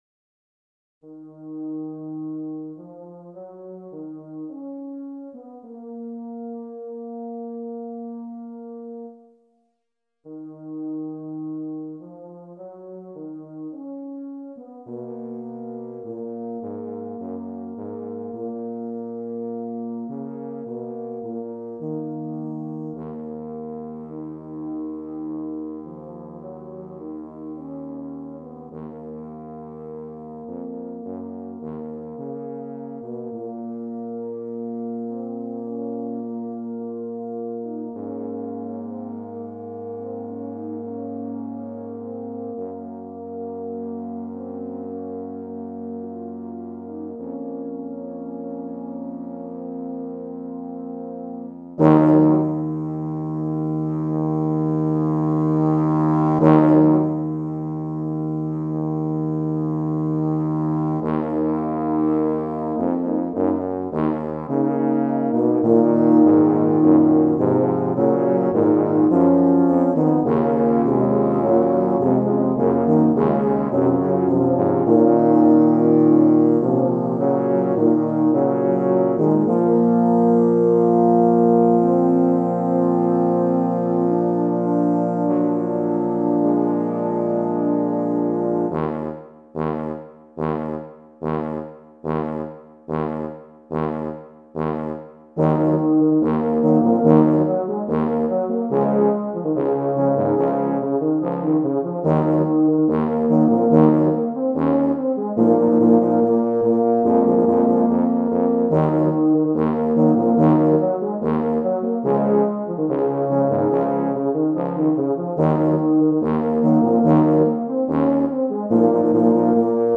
Voicing: Tuba Ensemble